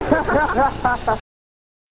And then they did something completely diabolical – they started laughing right in our faces!
Oh yes, they laughed.
bodydonnalaugh.rm